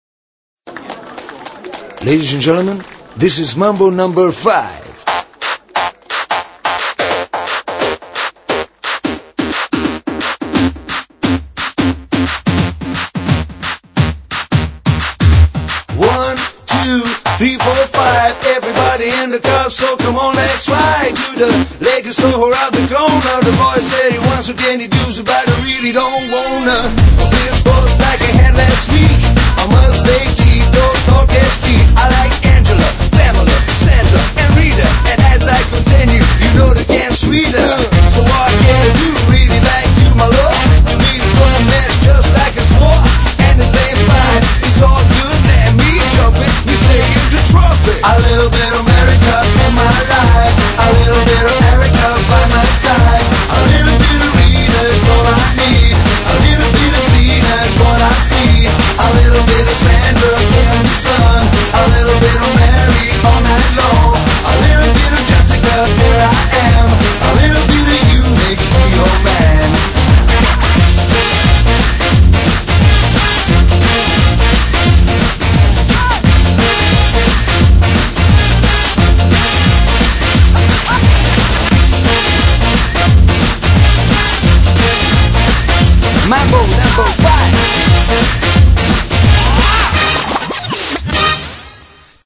BPM175--1
Audio QualityPerfect (High Quality)